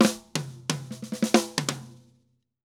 Drum_Break 136-2.wav